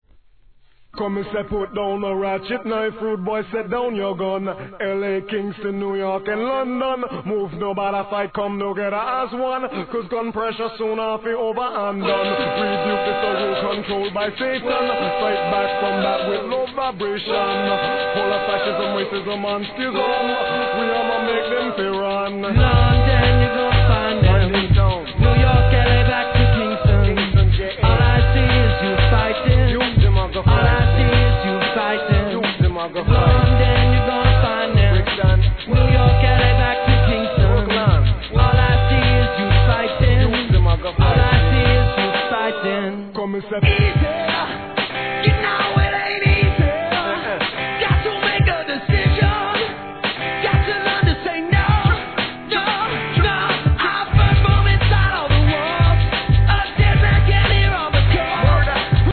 REGGAE
レゲエ、ヒップ ホップ、ドラムンベース、DUBが絡み合う見事なNEW YORKサウンドでのメッセージ!!